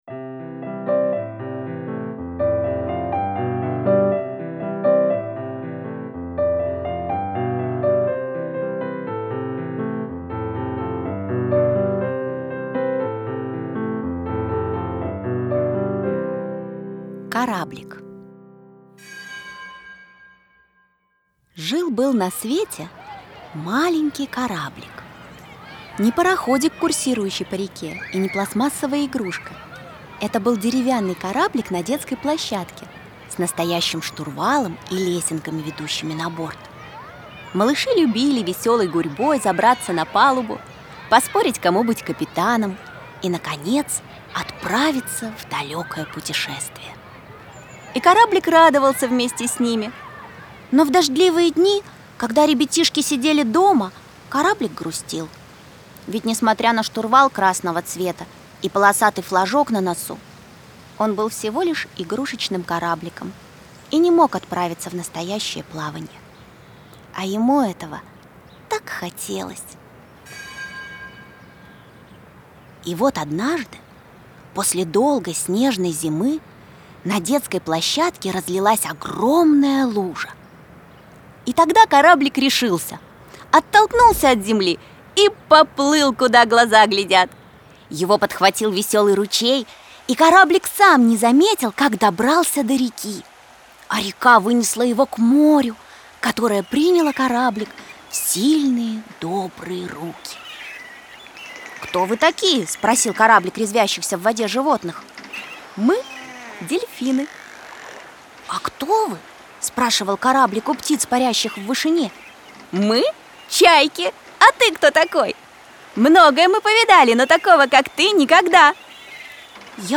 Кораблик - аудио рассказ Артемкиной - слушать онлайн
Фортепианная партия